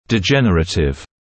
[dɪ’ʤenərətɪv][ди’джэнэрэтив]дегенеративный